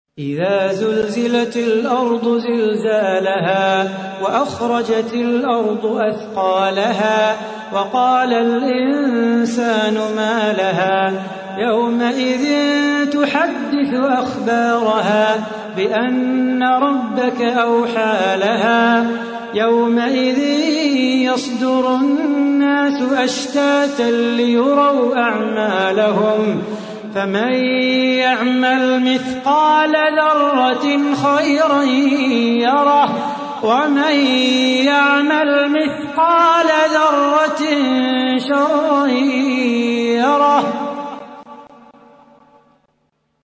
تحميل : 99. سورة الزلزلة / القارئ صلاح بو خاطر / القرآن الكريم / موقع يا حسين